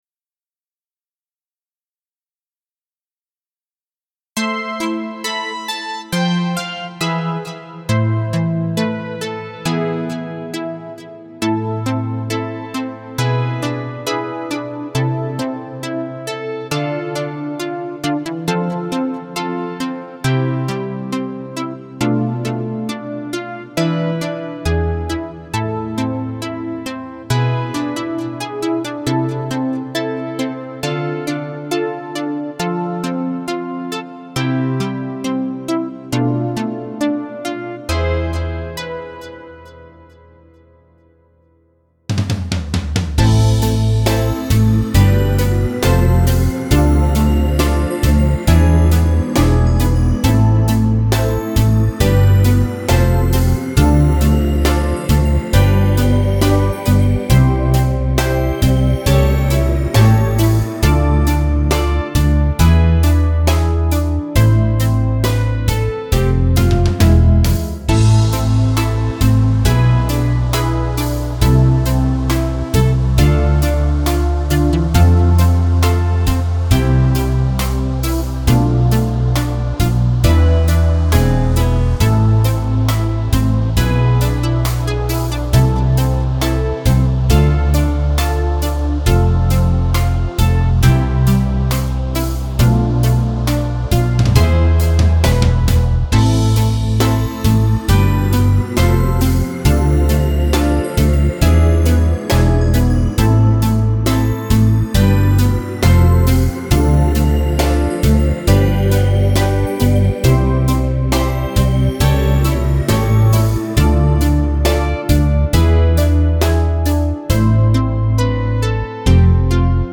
Слушать минус
караоке